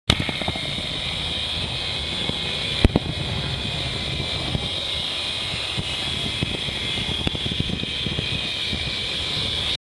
Еще один звук огня:
fire3.wav